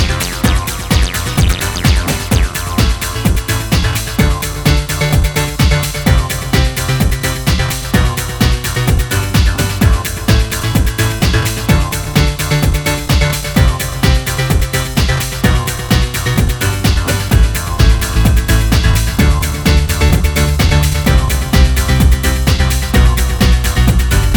催眠的なアシッドリフと分厚いキックで引っ張り、ユーフォリックなピアノで昇天させる